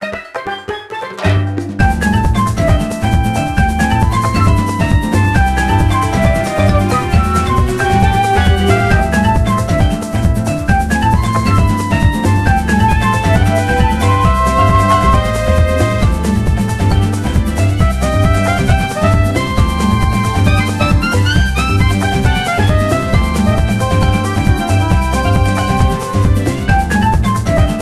minigame music rearranged
Source Video Game Music